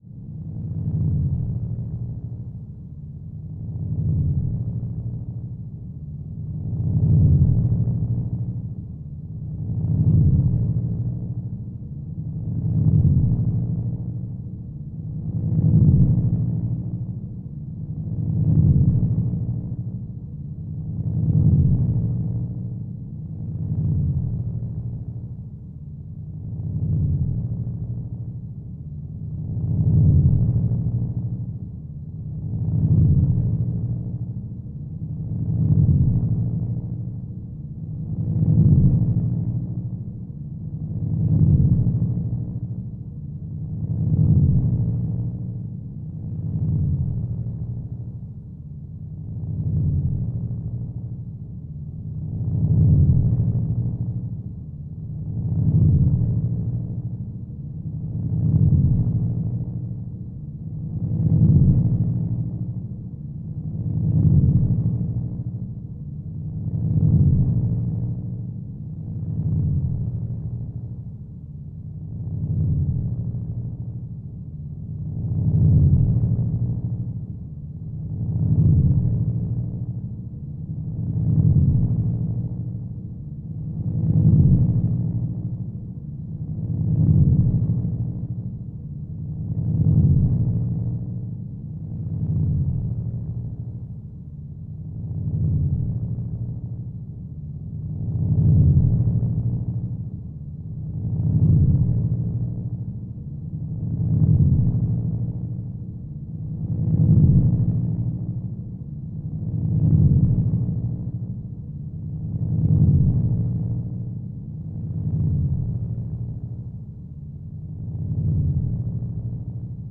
Ambiance, Machine Pulse; Rhythmic, Doppler, Pulsing Machine - The Pulse Comes And Goes More Quickly Than 17-1